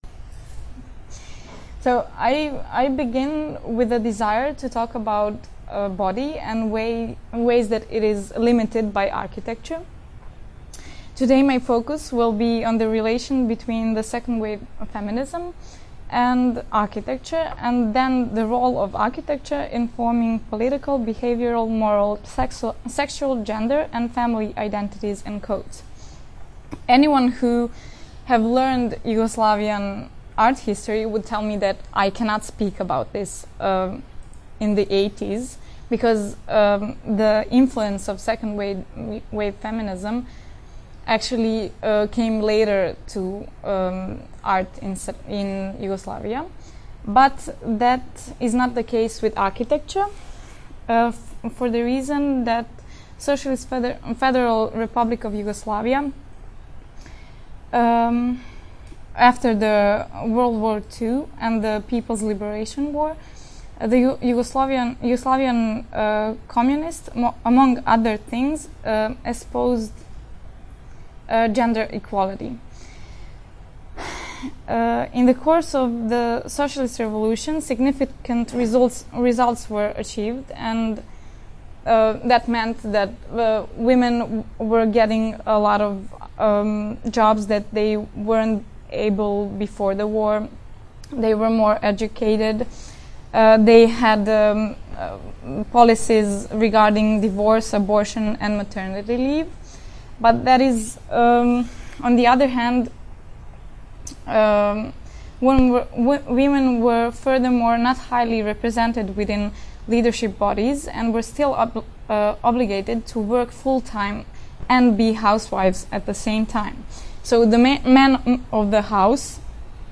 Presentation recordings: